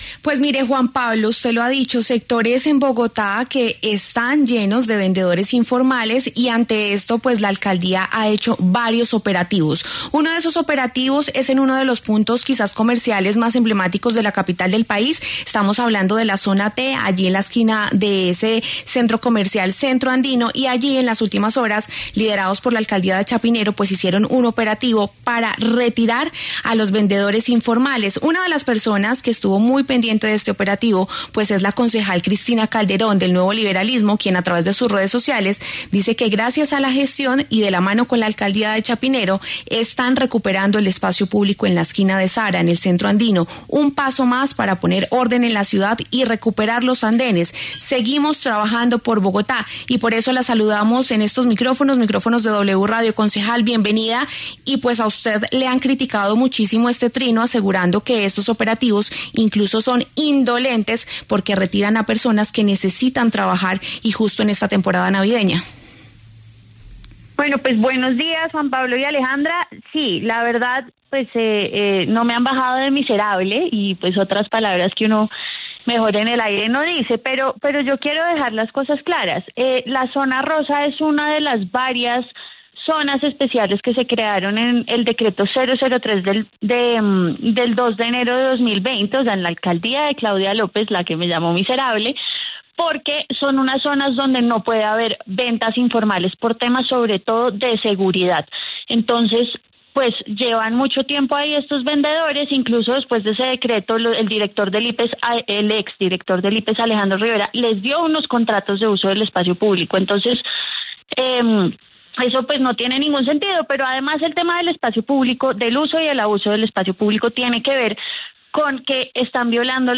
La concejal Cristina Calderón, del Nuevo Liberalismo, y el senador Inti Asprilla, de la Alianza Verde, pasaron por los micrófonos de La W.